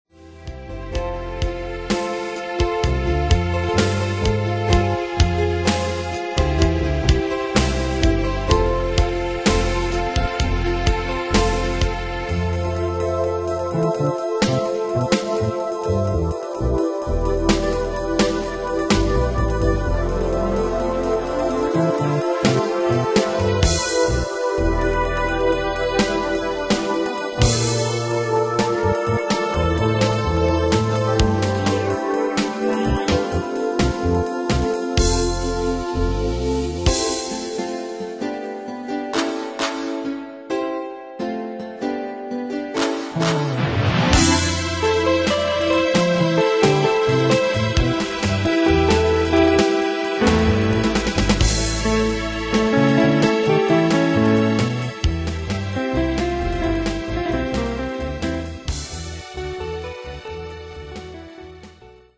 Style: Pop Rock Année/Year: 1969 Tempo: 127 Durée/Time: 5.27
Danse/Dance: Slow Rock Cat Id.
Pro Backing Tracks